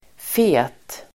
Uttal: [fe:t]